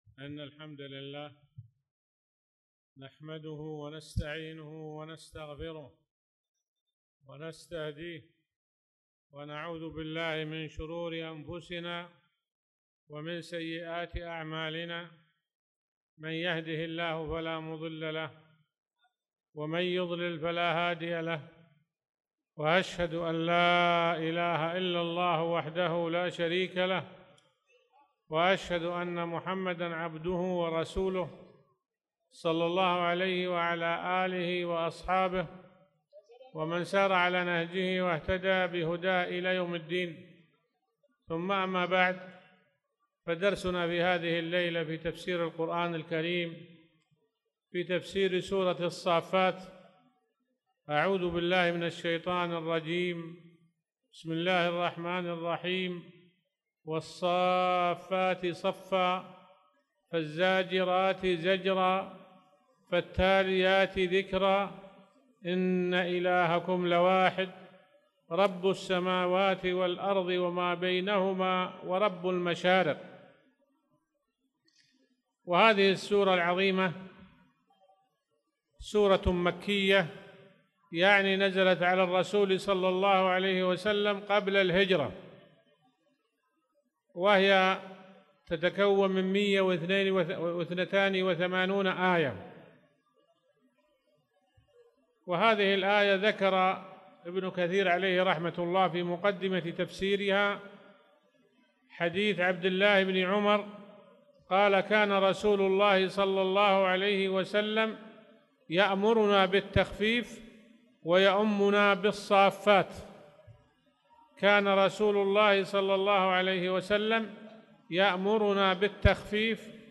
تاريخ النشر ٣ شعبان ١٤٣٧ المكان: المسجد الحرام الشيخ